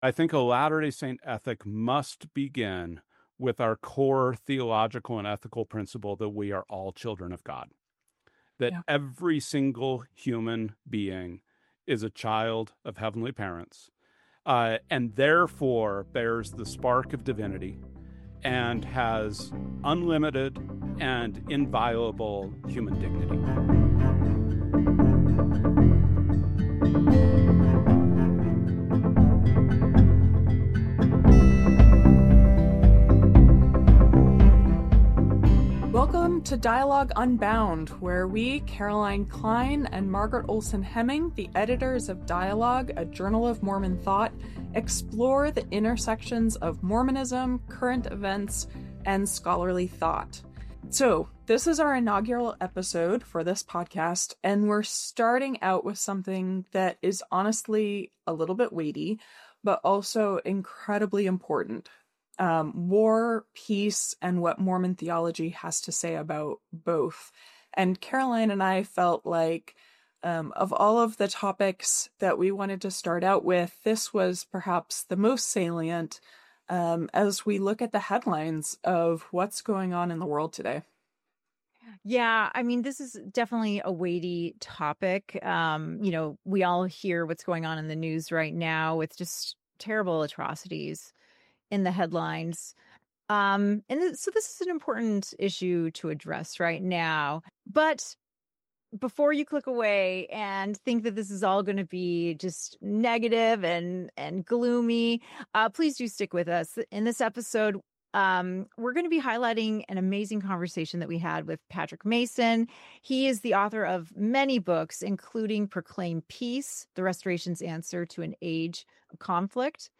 Mormonism-and-Peacebuilding-Full-Interview-audio-v2.mp3